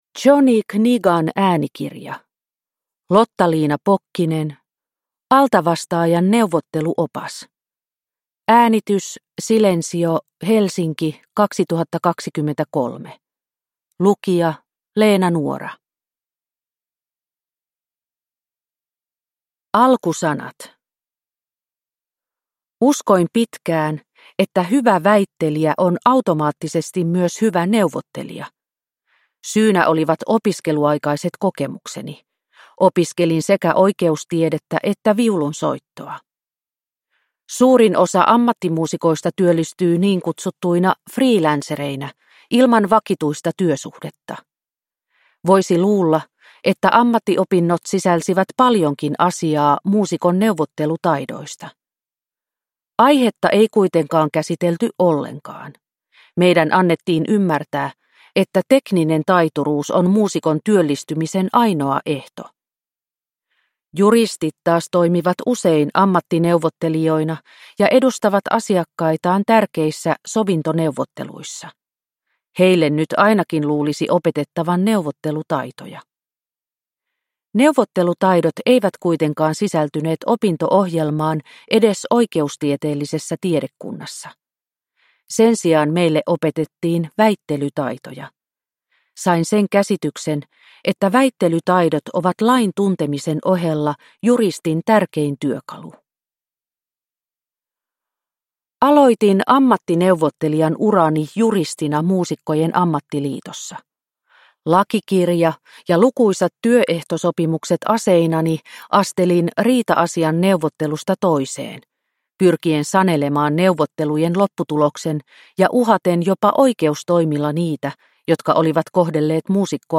Altavastaajan neuvotteluopas – Ljudbok – Laddas ner